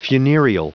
Prononciation du mot funereal en anglais (fichier audio)
Prononciation du mot : funereal